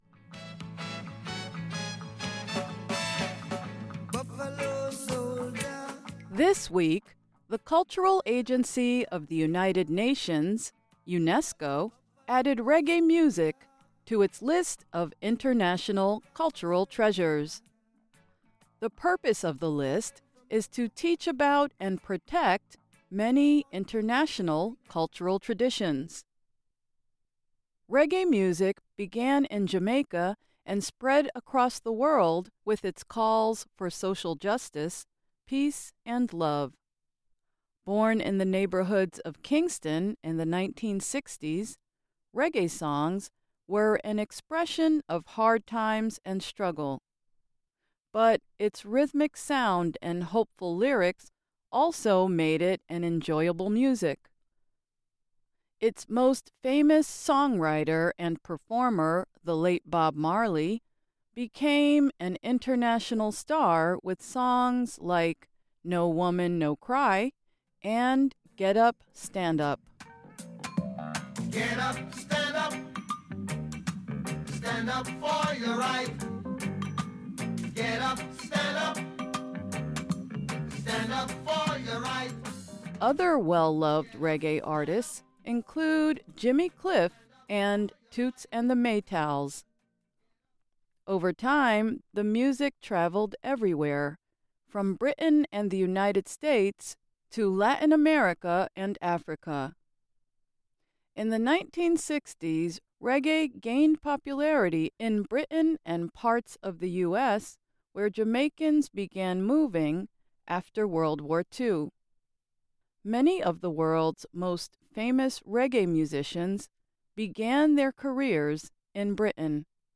Songs Used:
Bob Marley - "Get Up, Stand Up"
Bob Marley - "Buffalo Soldier"